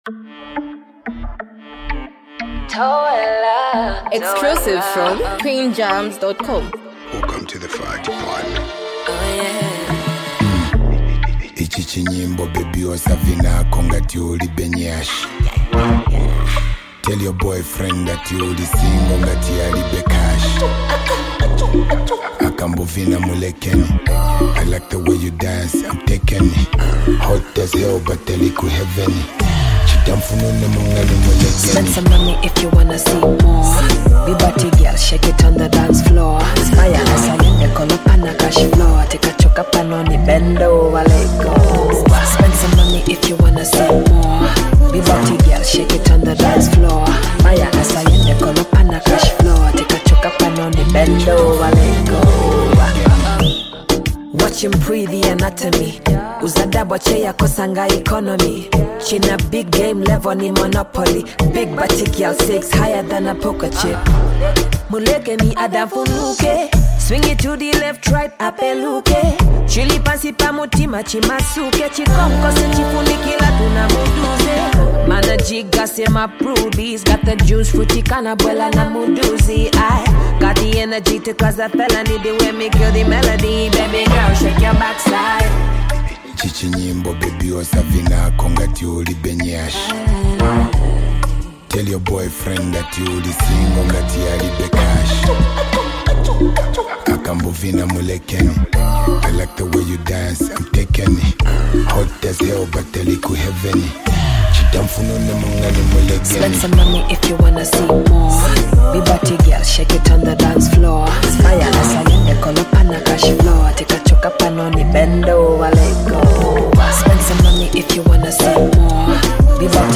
Highly multi talented act and super creative Female Singer